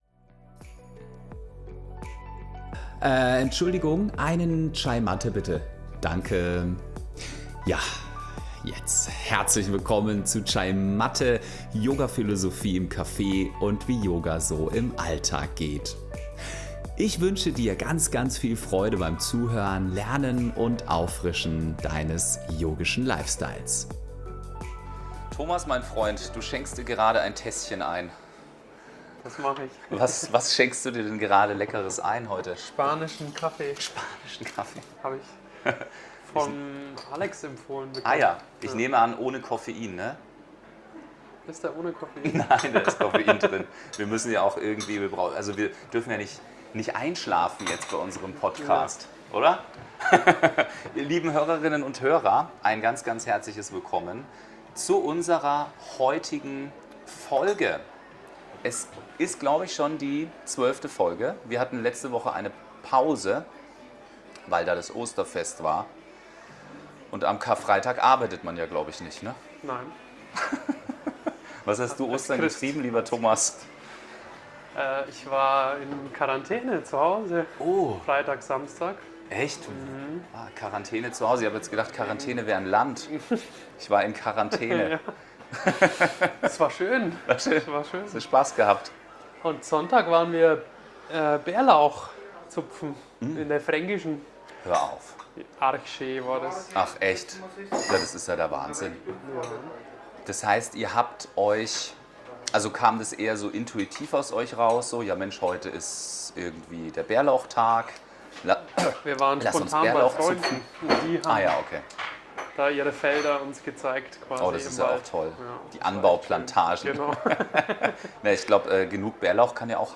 In dieser Episode unterhalten wir uns einmal mehr über das Thema Ziele.